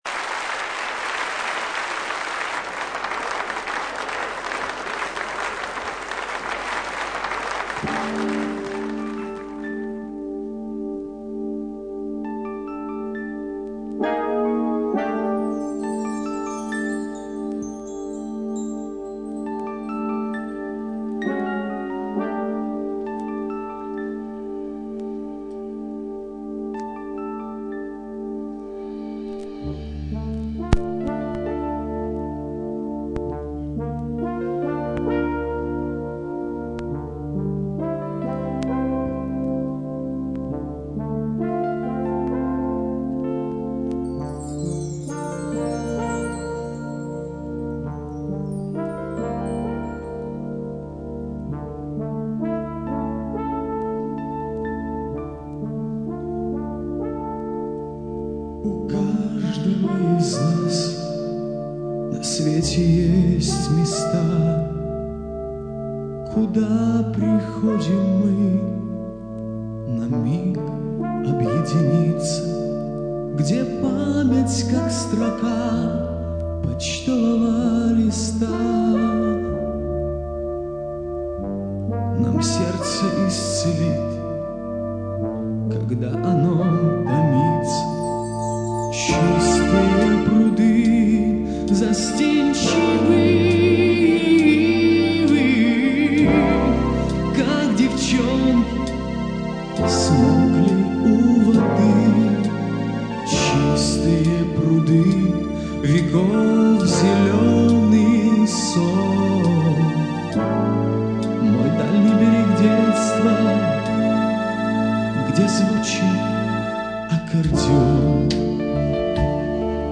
Качественных записей его выступления не сохранилось.
Запись песни с Юрмалы 1987 года